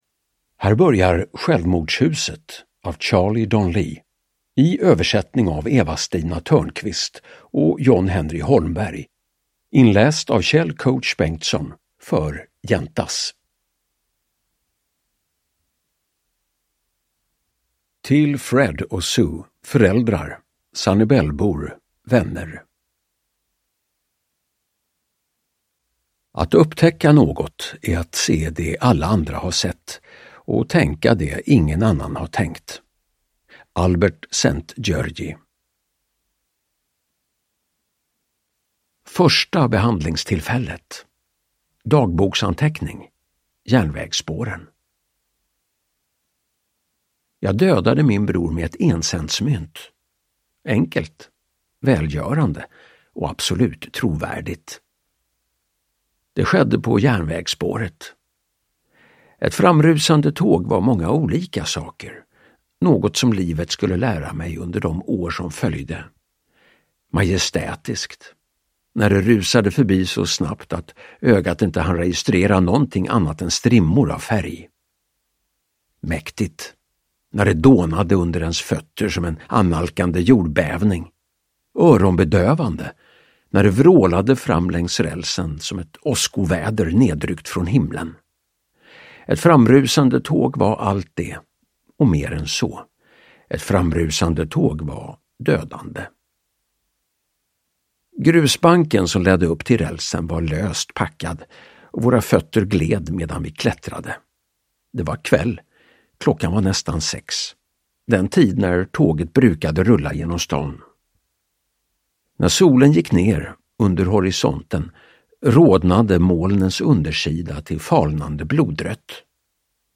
Självmordshuset (ljudbok) av Charlie Donlea